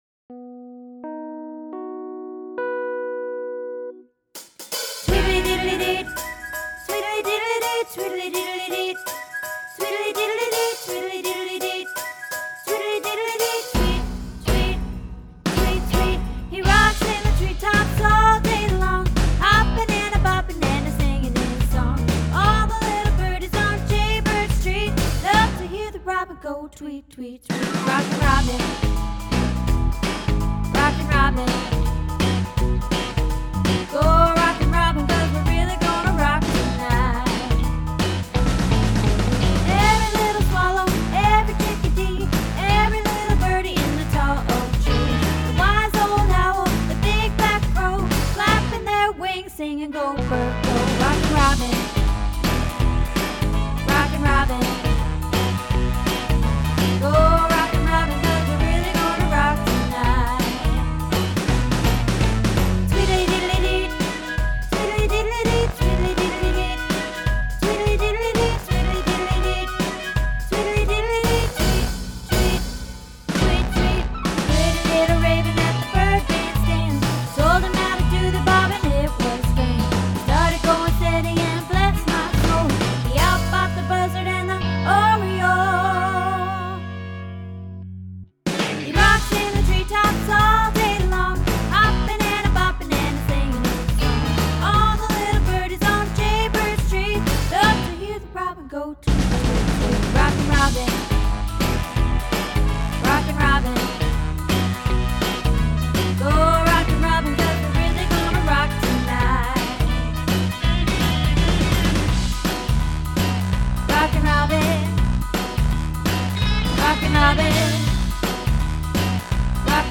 Rockin Robin - Alto